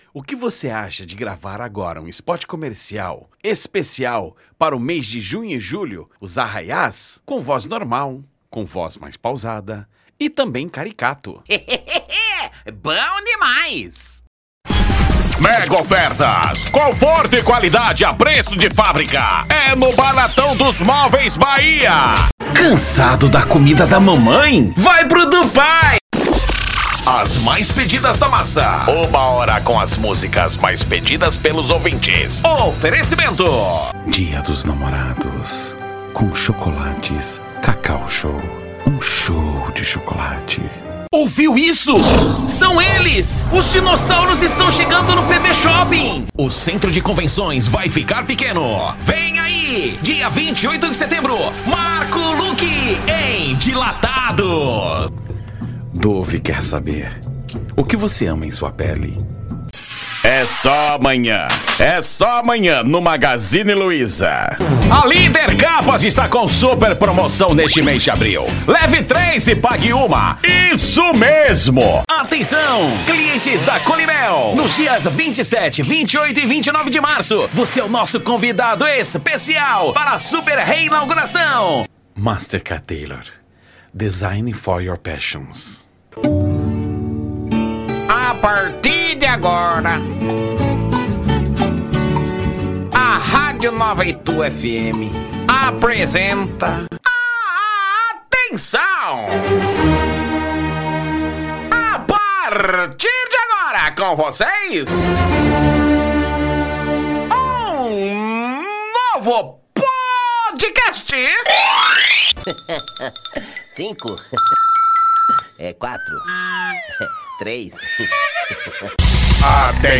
Padrão
Impacto
Animada
Caricata